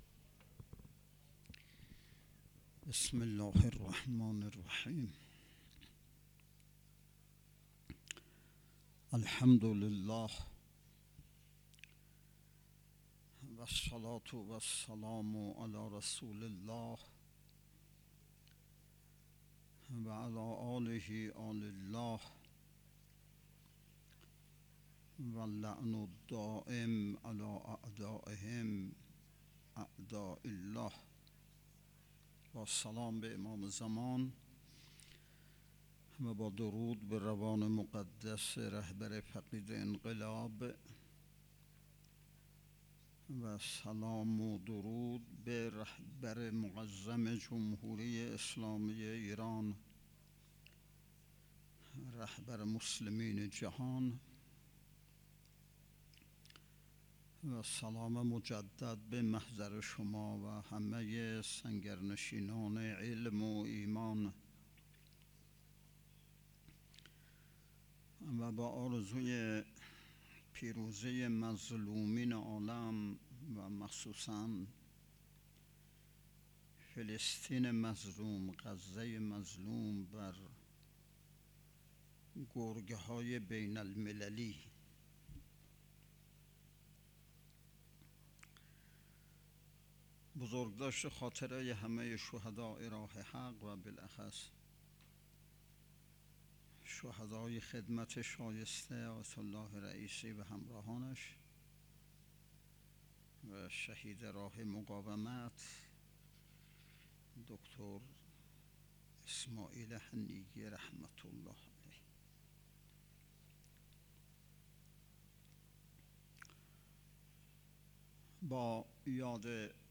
هشتمین نشست ارکان شبکه تربیتی صالحین بسیج با موضوع تربیت جوان مؤمن انقلابی پای کار، صبح امروز ( ۱۸ مرداد ) با حضور و سخنرانی نماینده ولی فقیه در استان، برگزار شد.